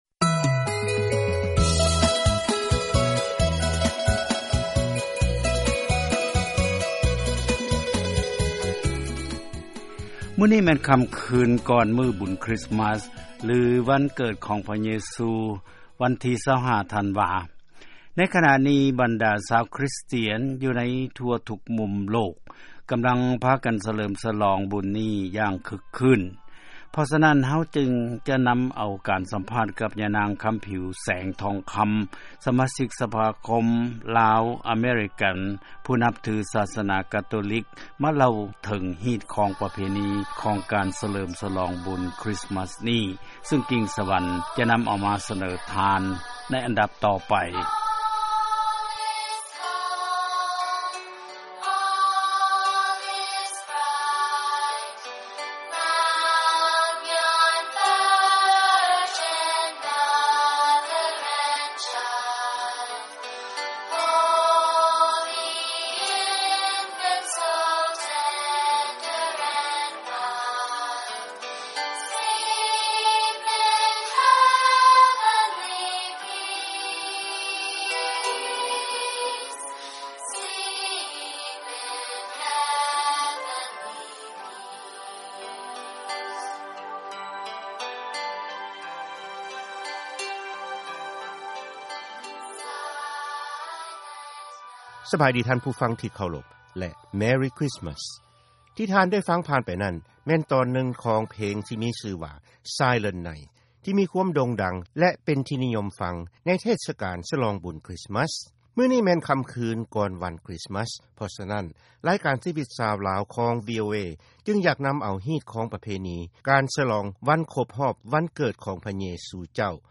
ເຊີນຟັງ ການສຳພາດກ່ຽວກັບ ການສະຫລອງບຸນຄຣິສມັສ